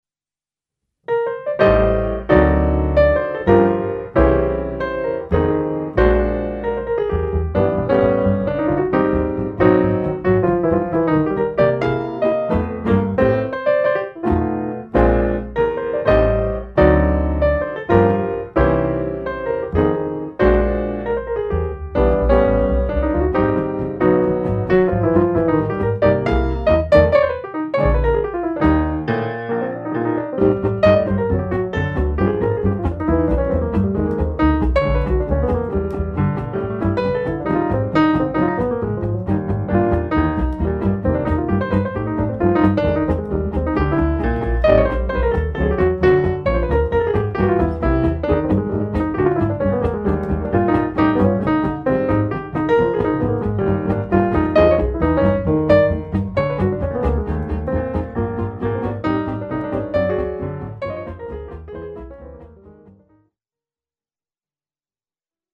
piano
guitare
contrebasse